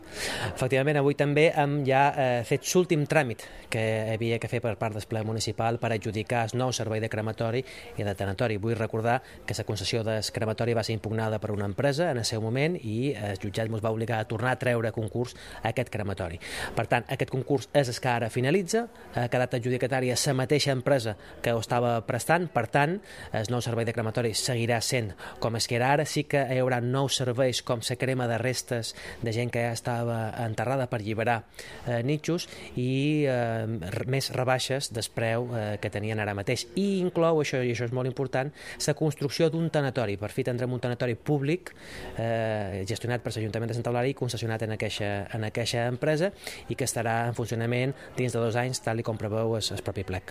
Corte de voz Concejal de Urbanismo Mariano Juan-Crematorio y Tanatorio